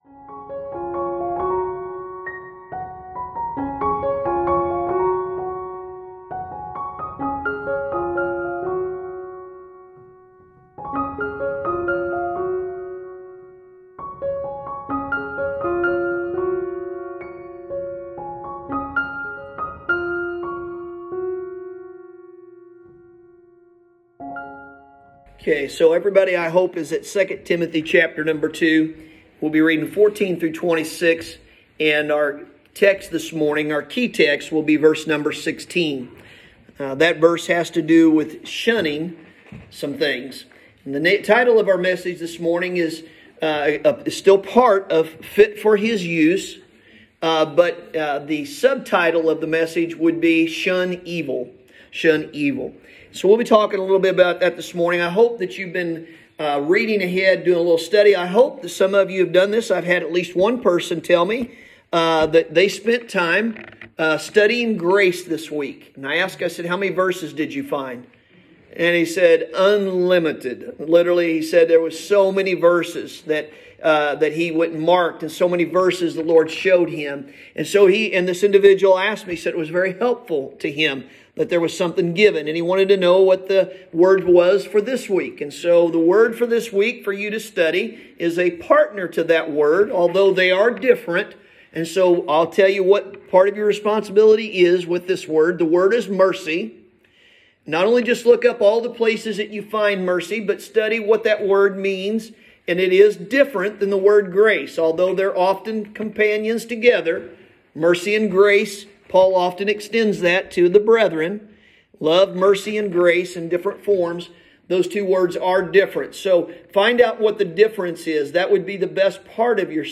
Sunday Morning – December 13th, 2020